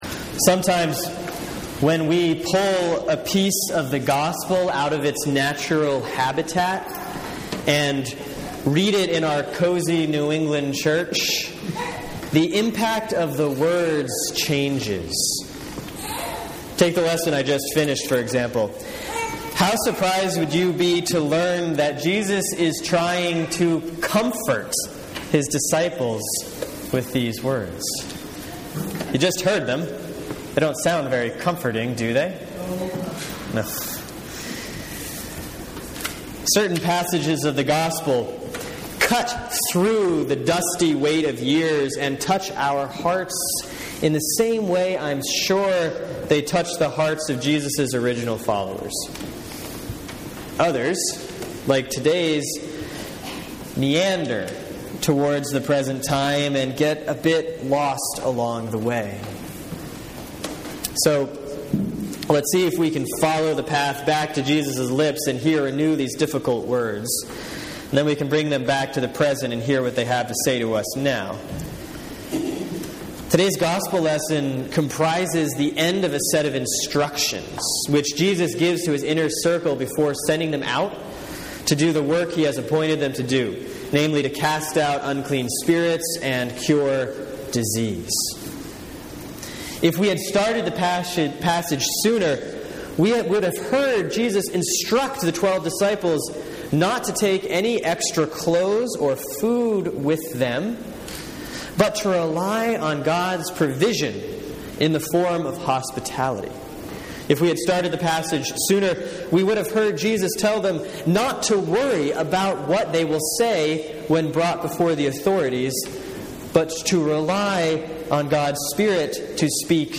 Sermon for Sunday, June 22, 2014 || Proper 7A || Matthew 10:24-39